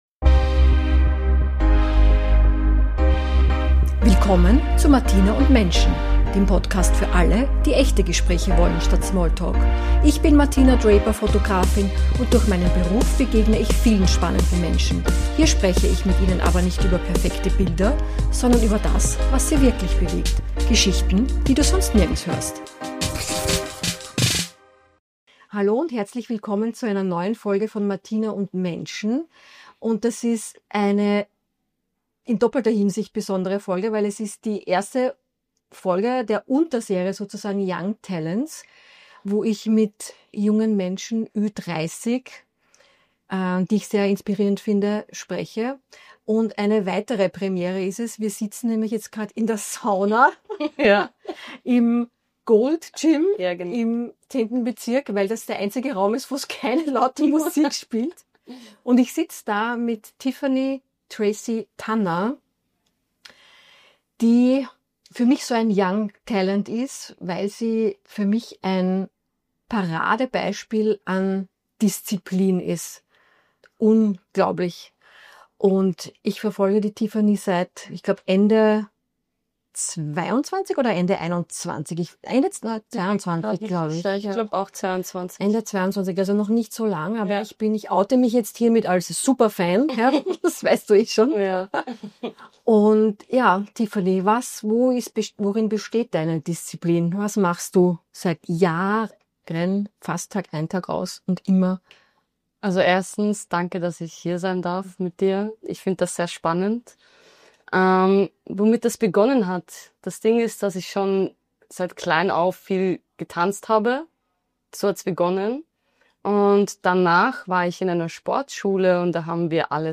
In dieser Young-Talents-Folge spreche ich mit einer jungen Frau über ihren Weg vom Gym als Safe Space bis zu vier Jahren beim Bundesheer als Panzergrenadierin – als einzige Frau im Zug.